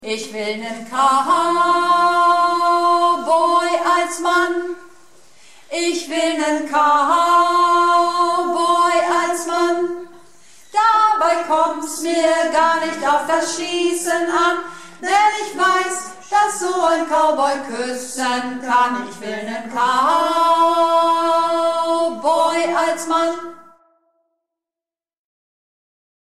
Projektchor "Keine Wahl ist keine Wahl" - Probe am 21.05.19
Runterladen (Mit rechter Maustaste anklicken, Menübefehl auswählen)   Ich will nen Cowboy als Mann (Tiefe Stimme)